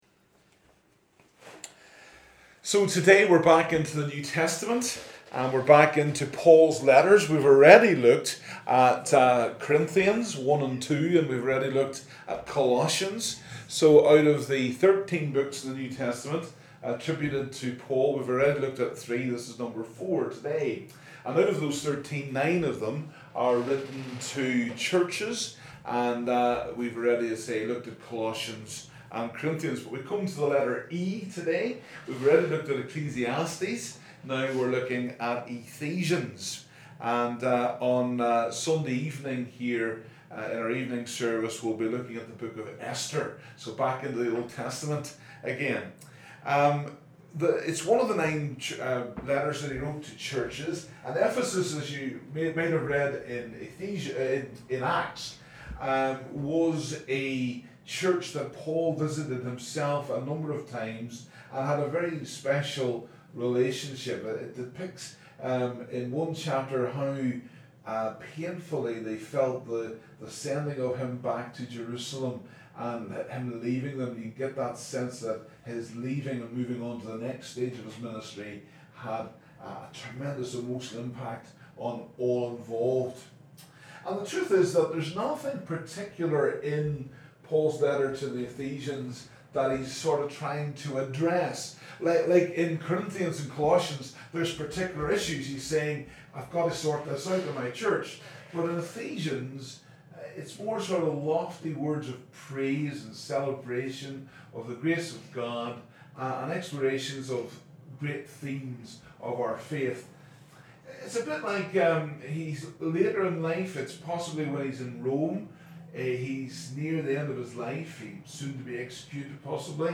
Download the live Session as an MP3 audio file (31.9 MB ) Listen to this talk